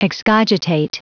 Prononciation du mot excogitate en anglais (fichier audio)
excogitate.wav